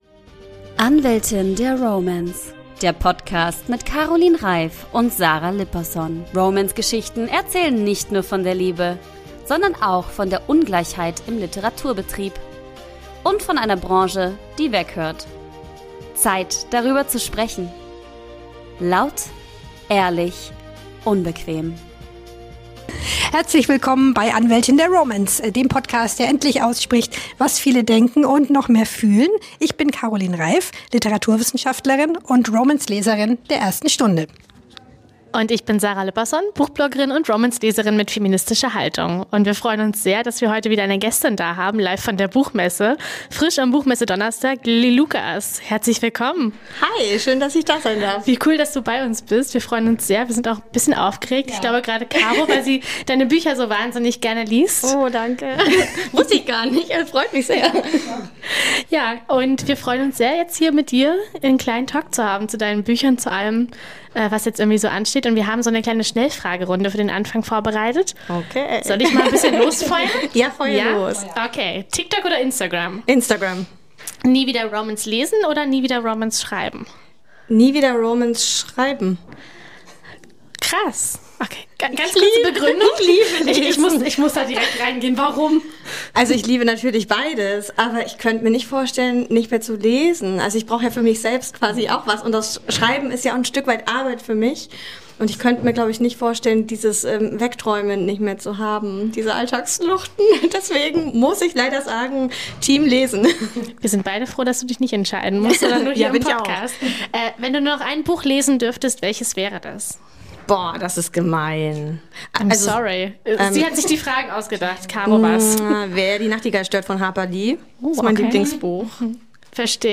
Beschreibung vor 3 Tagen Im deutschsprachigen Romance-Markt führt kein Weg an ihr vorbei – und wir haben sie auf der Buchmesse zum Interview getroffen: Lilly Lucas! Mit ihr sprechen wir über ihren Mega-Erfolg, die Green-Valley-Reihe, die das Genre geprägt hat. Außerdem geht um die Anfänge von Romance, warum sie den Begriff „Frauenliteratur“ nicht ausstehen kann und wie sie den Markt heute wirklich sieht.